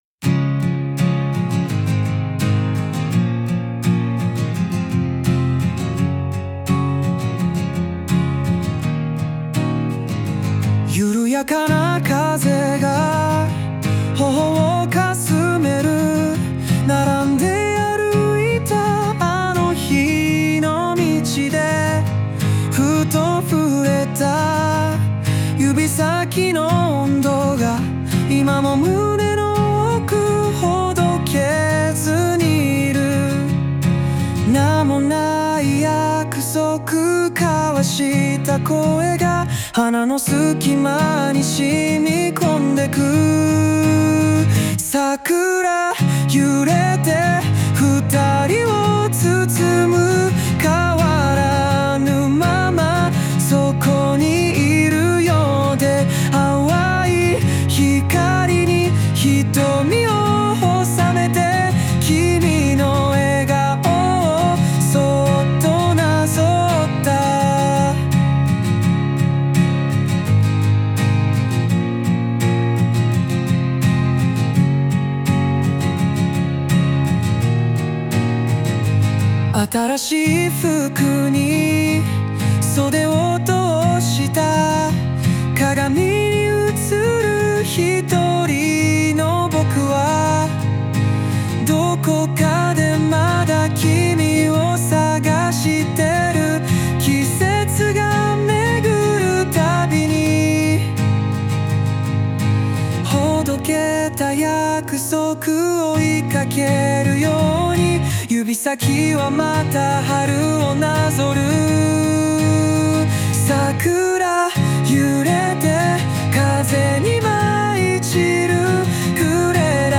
邦楽男性ボーカル著作権フリーBGM ボーカル
著作権フリーオリジナルBGMです。
男性ボーカル（邦楽・日本語）曲です。
優しい男性ボーカルに乗せてアコースティックバラードに仕上げた桜ソングです🌸